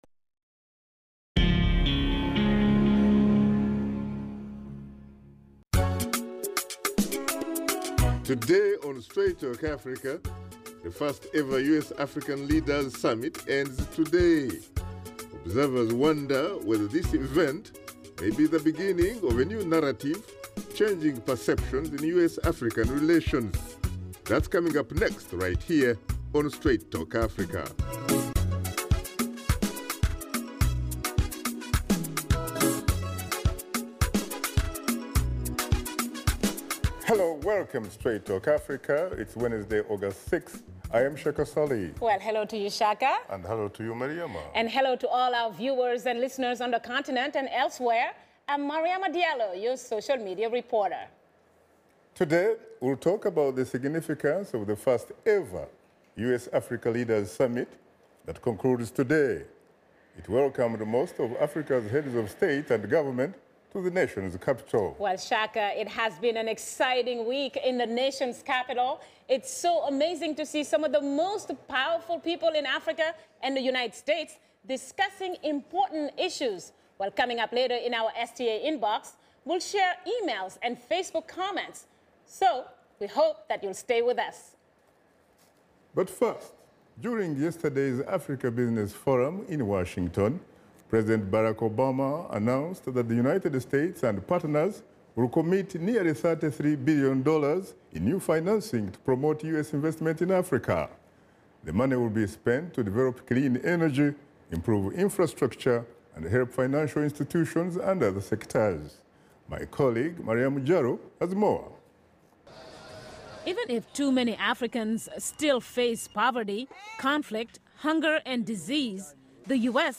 Host Shaka Ssali and his guests discuss the impact and significance of the first ever U.S.- Africa Leaders Summit.